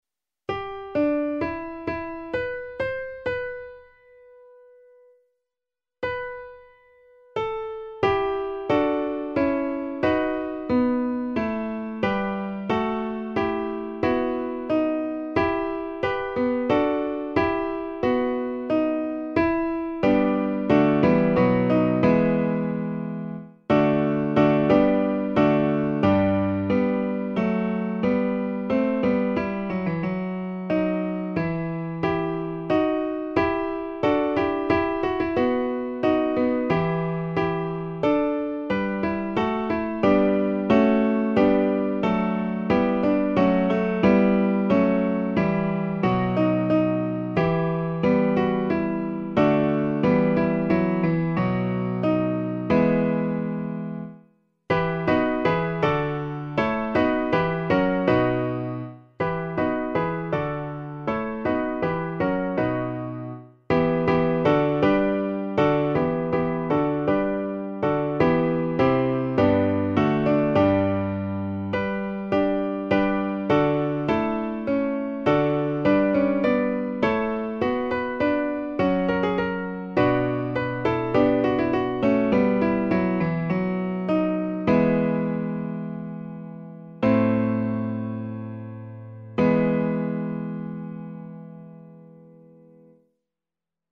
a 4 voces